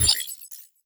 Robotic Game Notification 12.wav